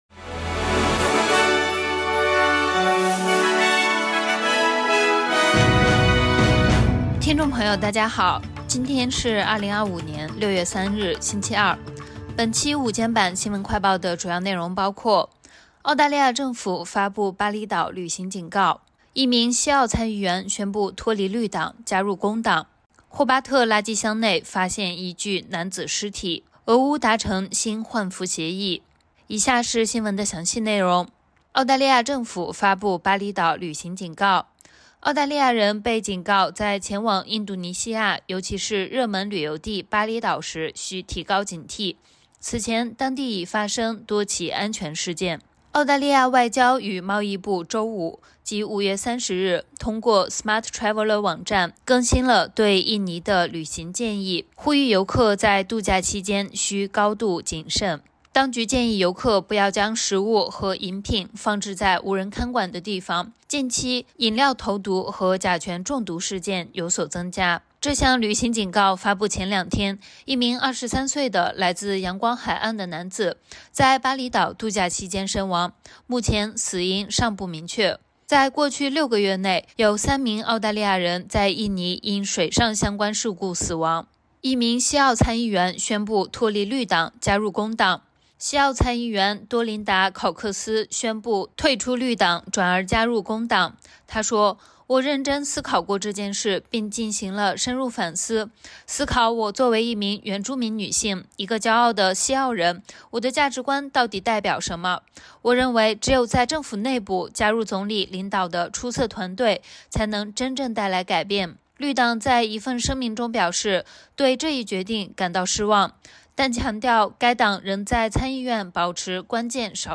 【SBS新闻快报】外交部提醒 前往该热门目的地的旅客需保持“高度警惕”